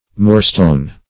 Moorstone \Moor"stone`\, n. A species of English granite, used as a building stone.